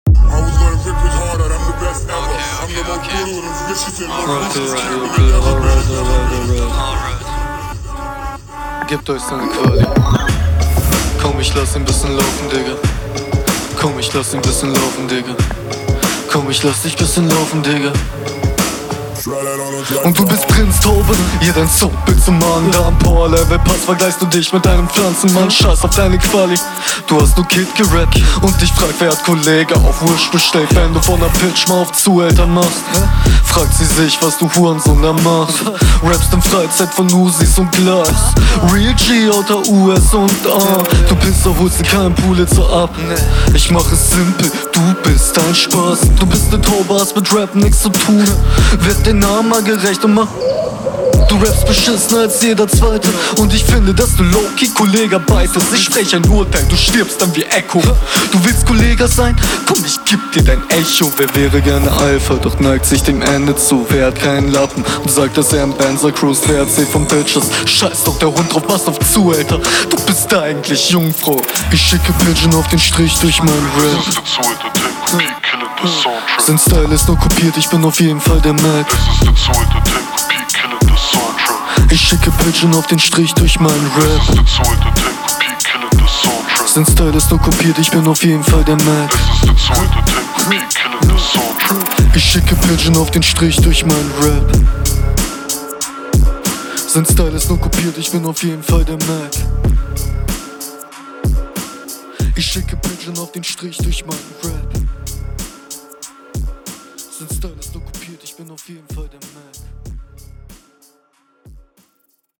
Der Flow wäre clean, wenn du manchmal nicht die Zeilenlänge ein bisschen überspannen würdest. zum …